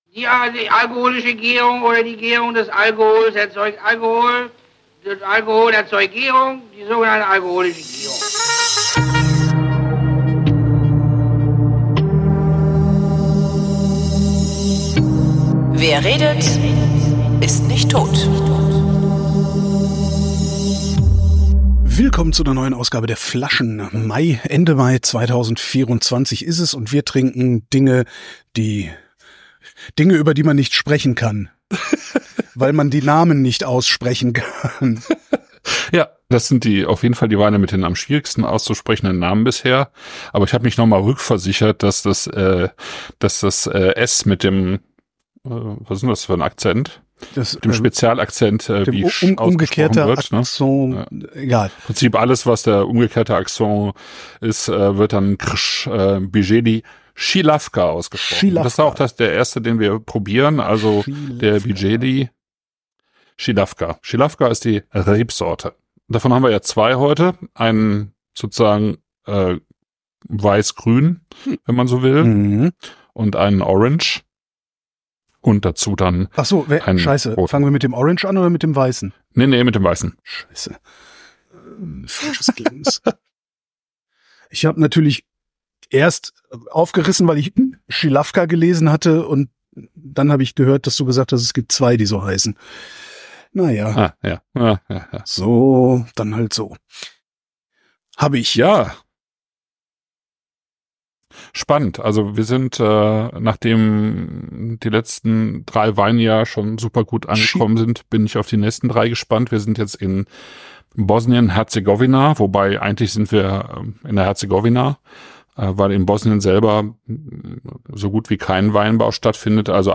wrint: gespräche zum runterladen
Wir trinken Zilavka, Blatina und Trnjak – alle drei von Škegro – und reden unter anderem über Lidl als Lebensmittelhersteller.